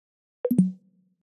Звуковые эффекты iMac и MacBook